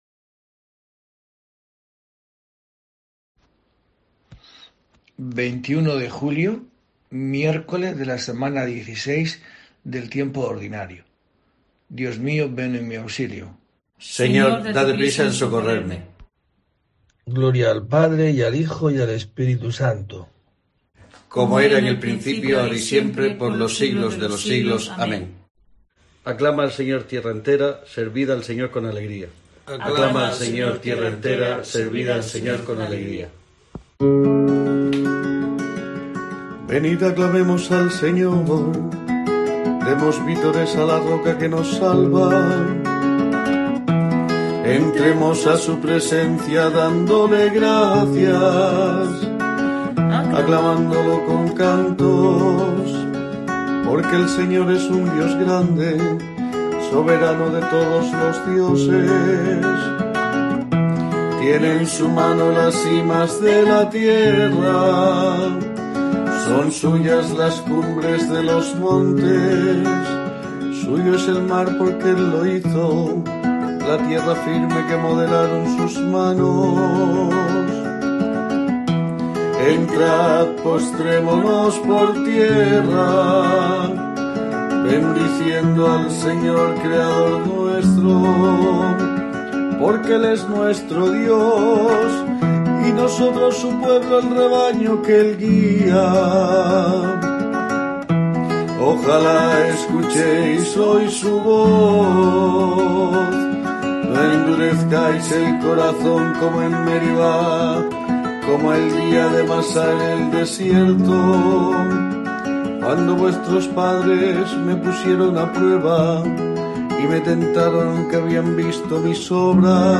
La oración desde la parroquia de Santa Eulalia de Murcia, pronunciada en este día